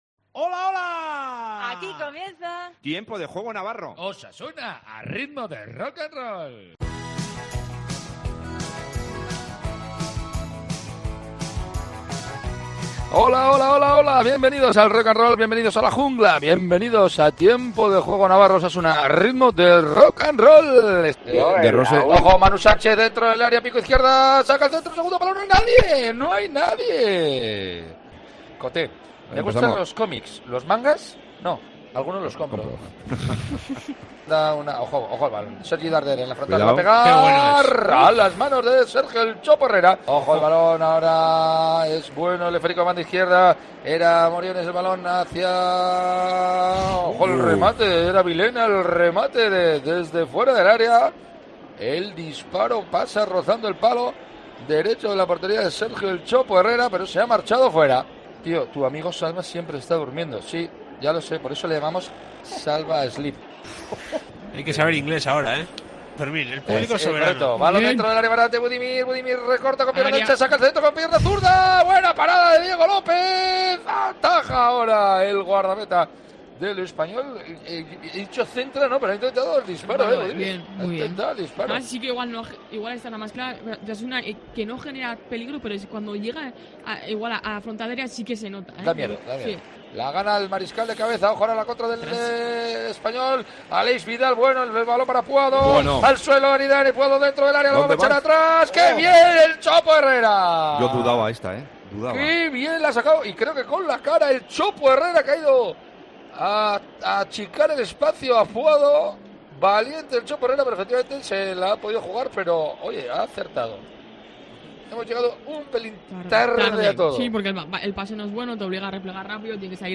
Revive los mejores momentos del empate a uno entre Osasuna y Espanyol en Barcelona con la narración